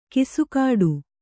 ♪ kisukāḍu